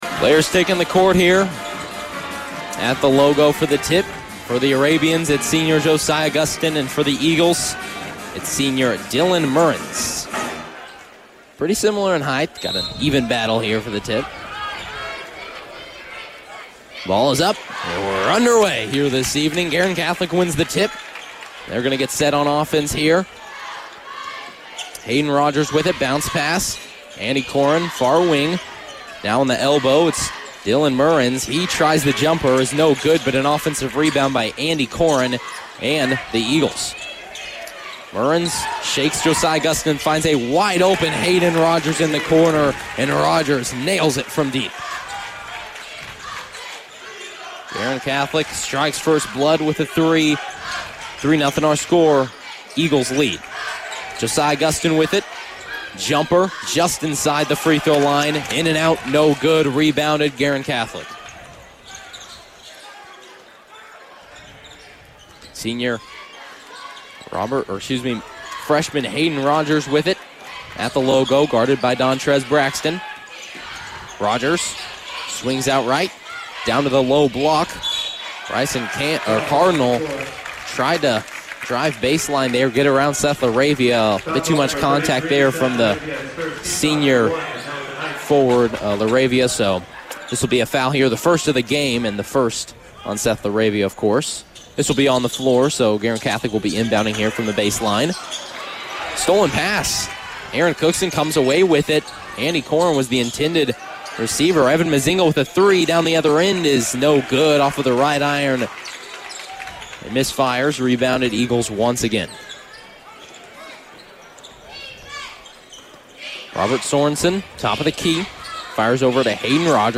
Varsity Boys Basketball Broadcast Replay Pendleton Heights vs. Guerin Catholic 12-12-23